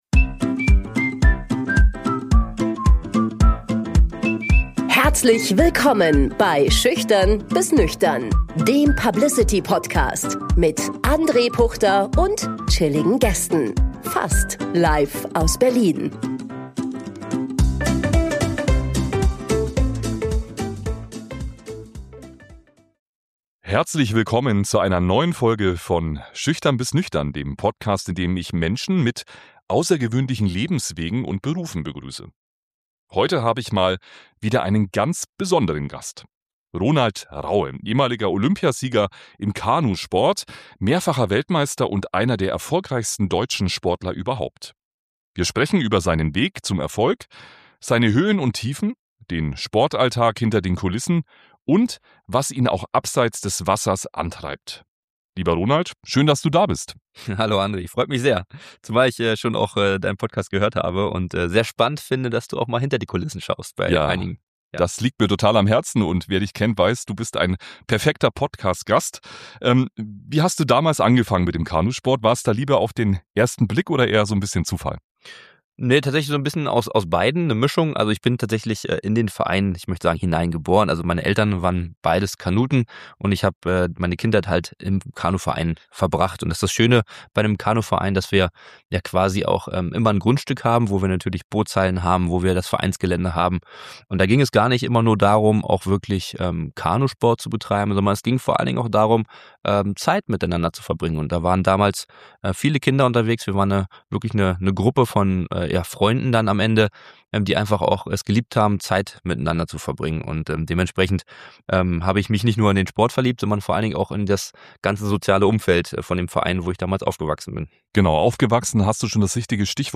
Es geht um große Siege, bittere Niederlagen, den Moment des Abschieds – und darum, wie man Ehrgeiz, Haltung und Verantwortung in ein Leben nach der Karriere überträgt. Ein Gespräch über Erfolg auf dem Wasser und Charakter an Land.